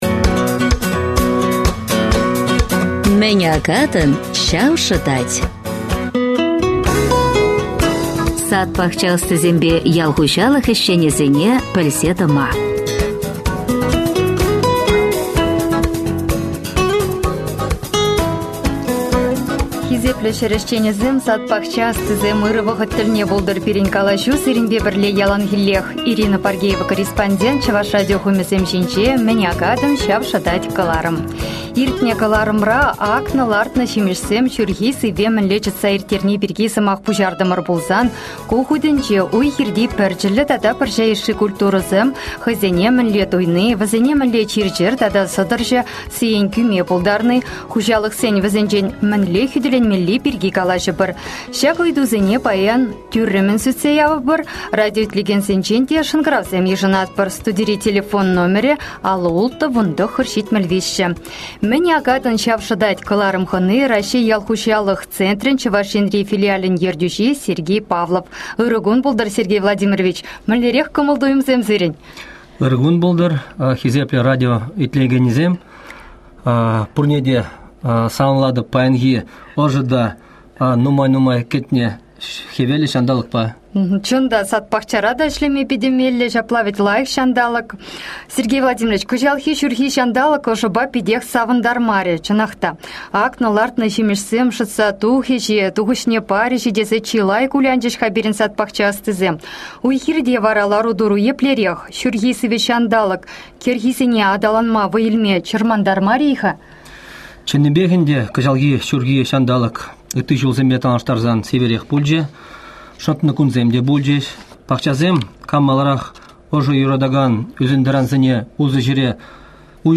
Выступление